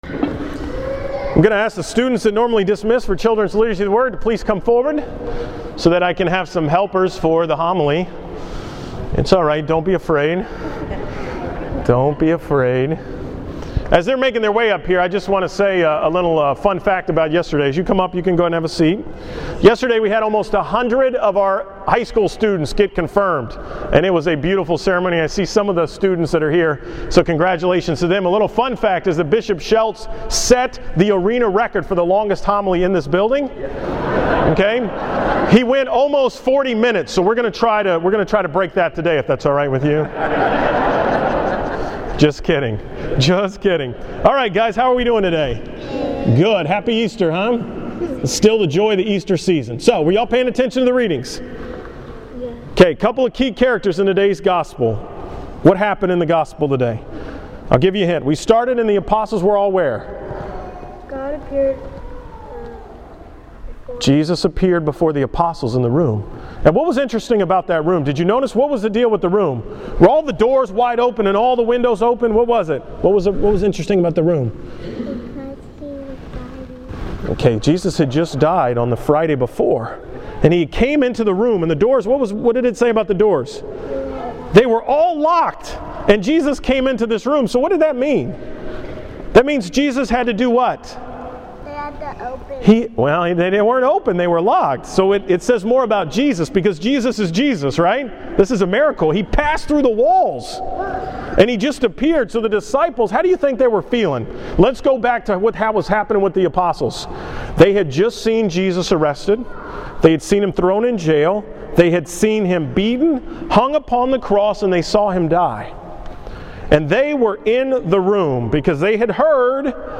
Homily at 9 am Mass from the Second Sunday of Easter Divine Mercy Sunday